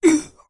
Vampire_Hurt5.wav